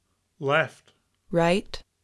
left_right.wav